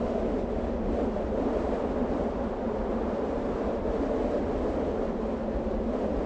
target_wind_float_loop.ogg